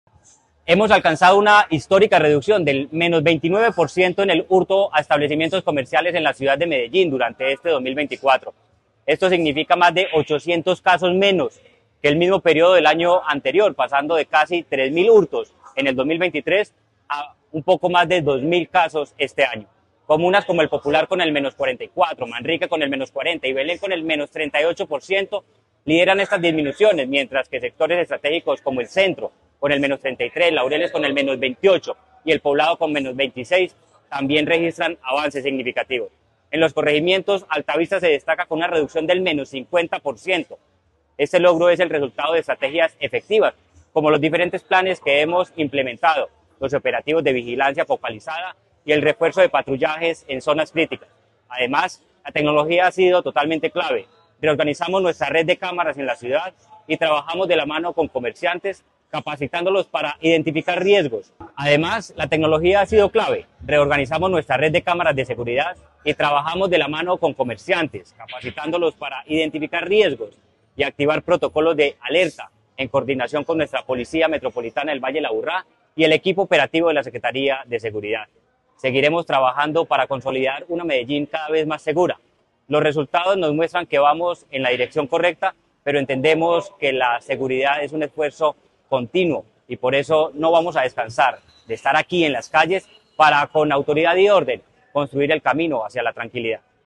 Palabras de Manuel Villa Mejía, secretario de Seguridad y Convivencia Medellín alcanzó una disminución del 29 % en el delito de hurto a establecimientos comerciales en lo que va de 2024.